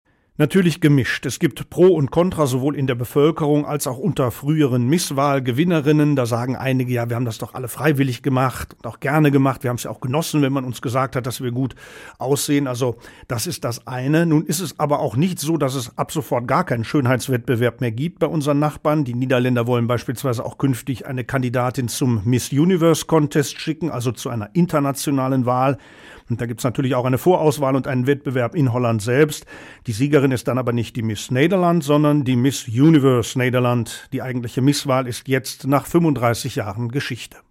Nachrichten Abschaffung der Miss-Wahl in den Niederlanden